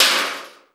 HR16B I-HIT2.wav